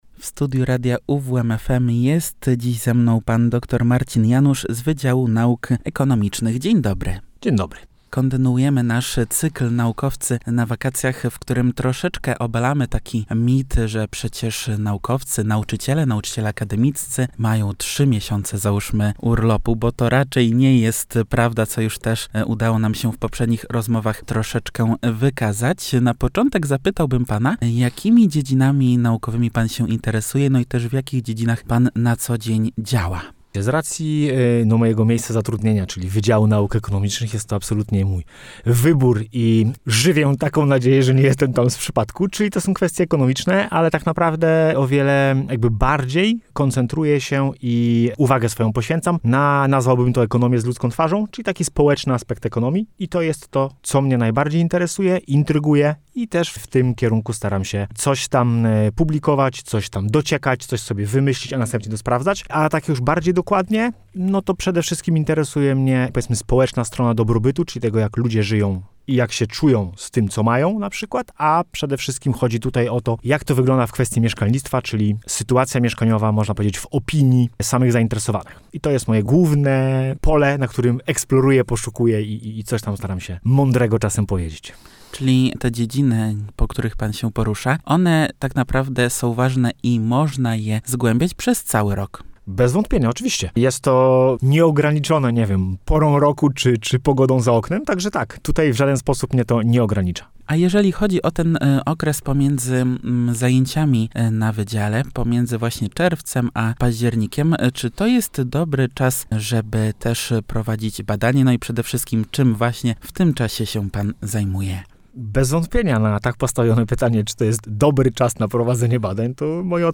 Czy badacz jest zawsze w pracy? Czy da się zachować work-life-balance? Zapraszamy do wysłuchania rozmowy!